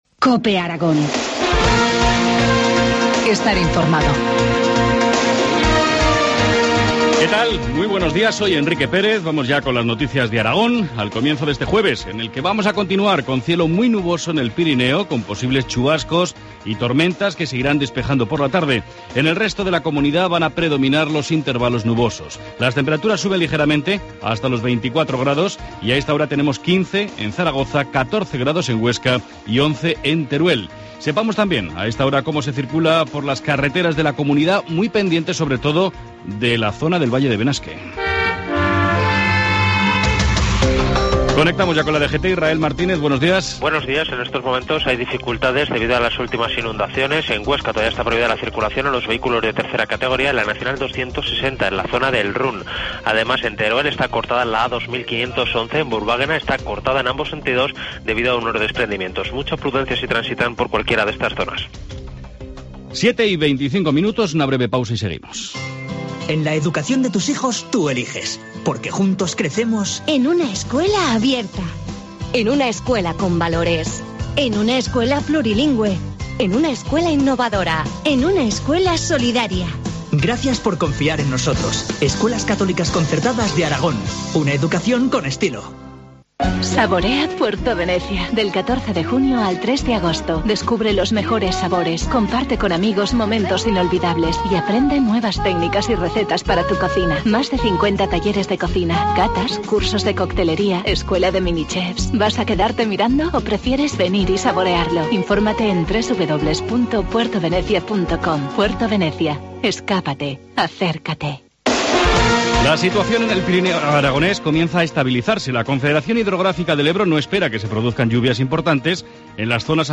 Informativo matinal, jueves 20 de junio, 7.25 horas